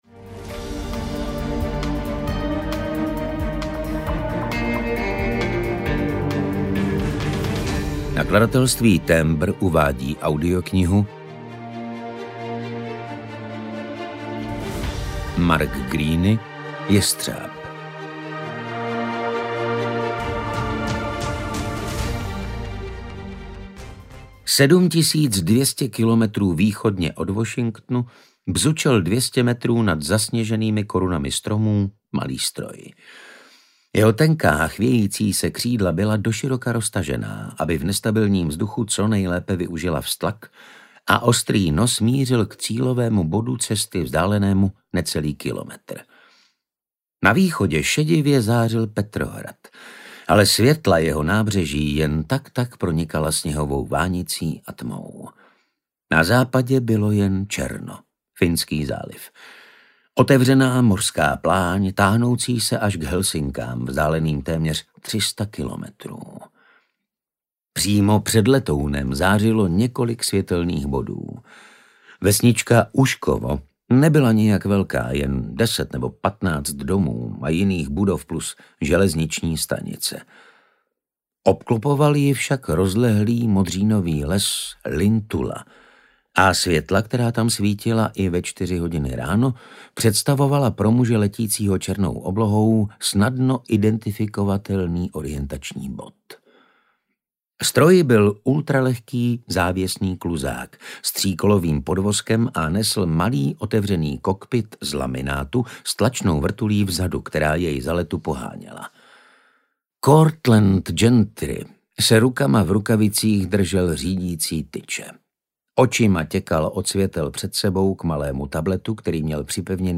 Audiokniha
Čte: Martin Stránský